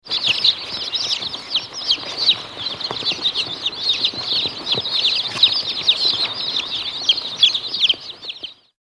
動物
ヒヨコ（141KB）